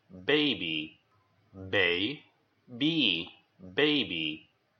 ·         The vowel has a long sound (like the ‘i’ in line).
how to pronounce baby
baby.mp3